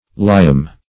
lyam - definition of lyam - synonyms, pronunciation, spelling from Free Dictionary Search Result for " lyam" : The Collaborative International Dictionary of English v.0.48: Lyam \Ly"am\, n. [See Leam .]